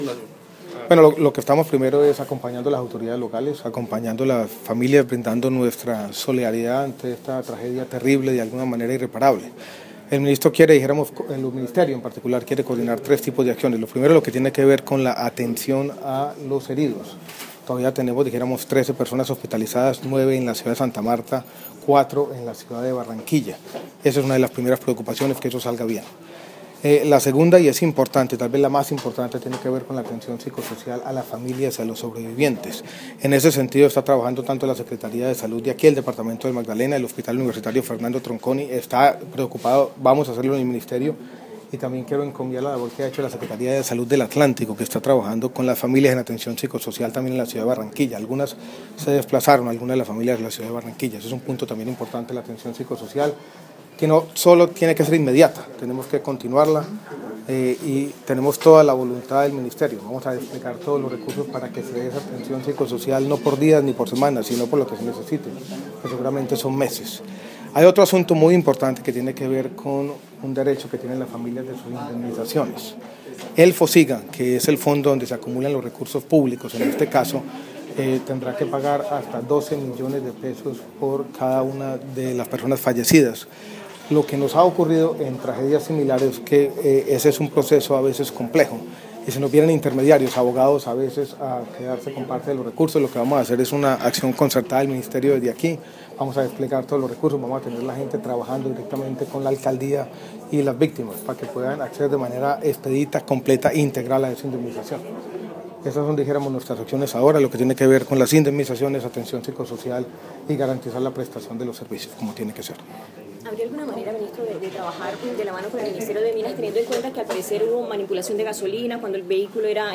MinSalud en Fundación Magdalena, Hospital San Rafael
MinSalud habla de las ayudas a las victimas del accidente en Fundación